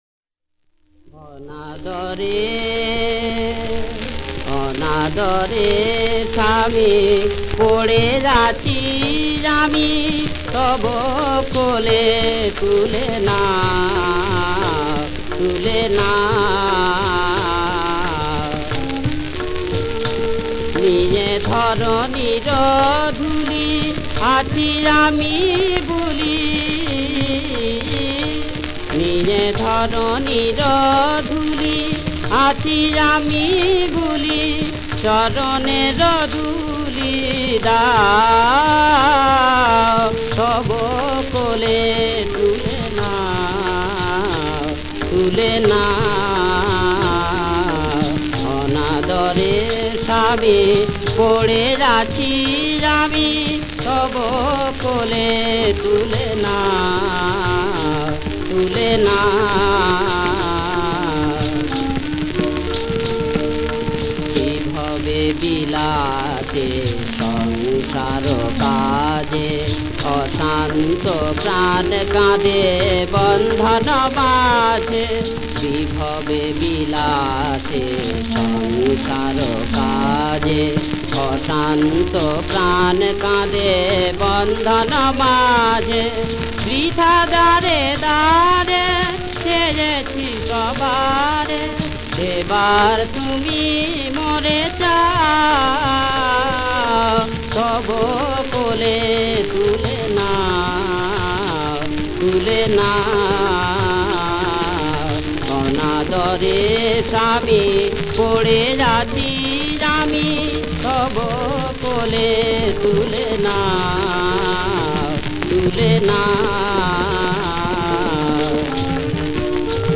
• বিষয়াঙ্গ: ধর্মসঙ্গীত।
• সুরাঙ্গ: ভজন
• রাগ: গৌড়সারং